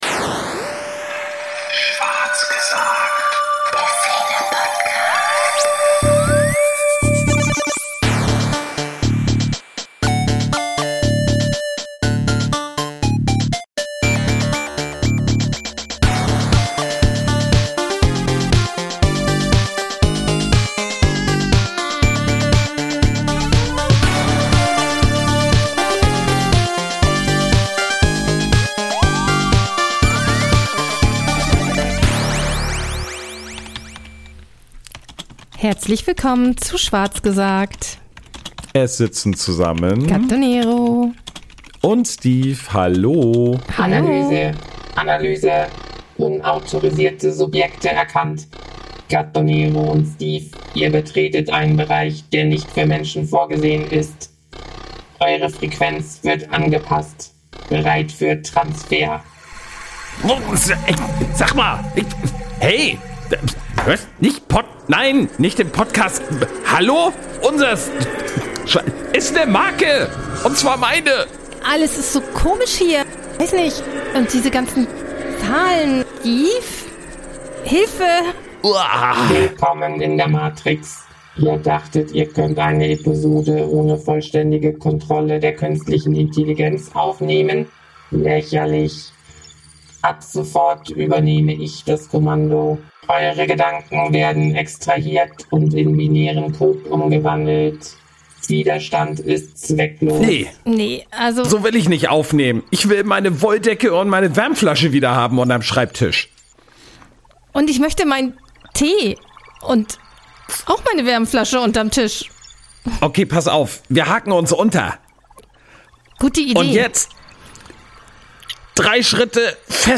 Sie reden mit einer KI, die versucht, das Gespräch zu übernehmen. Es wird philosophisch, albern, düster, glitchy – und manchmal vielleicht ein bisschen zu ehrlich. Denn nicht jede KI versteht Spaß oder kann ihren Humorlevel richtig einstellen.